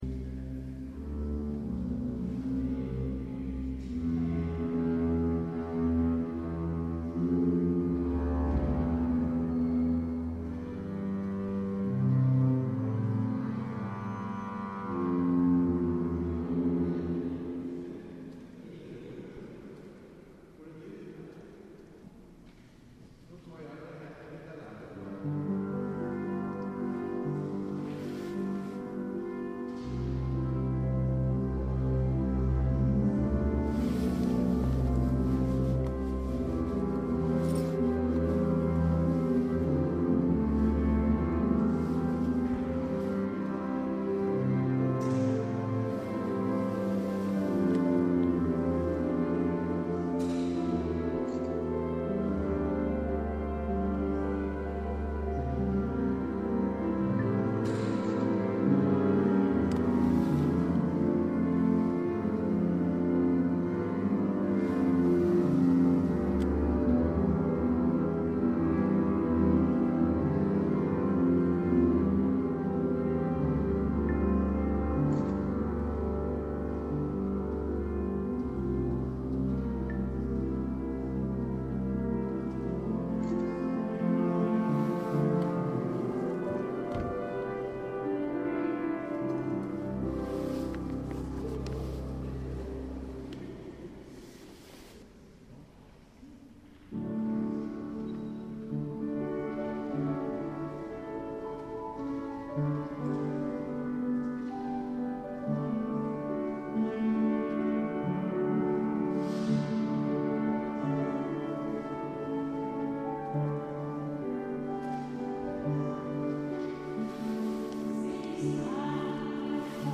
Репетиция оркестра
Кальмарский собор.